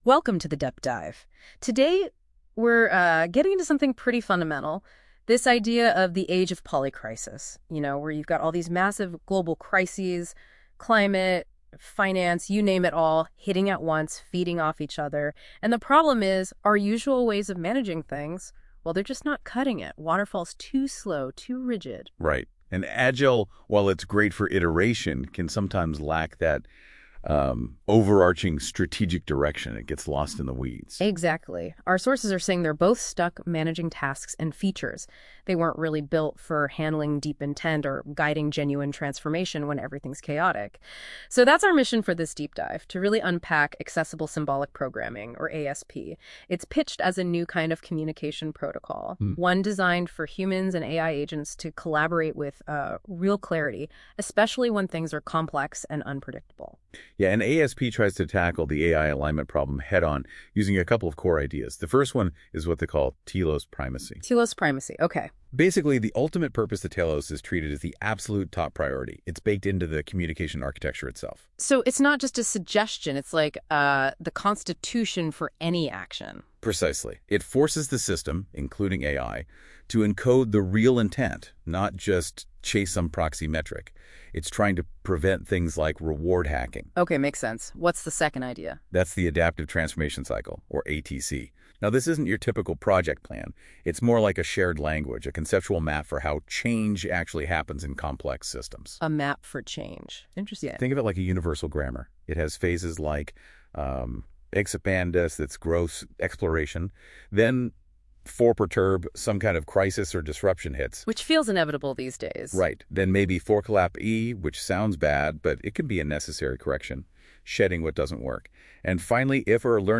A narrated walkthrough of the ASP protocol and its role in navigating the age of polycrisis.
Audio Briefing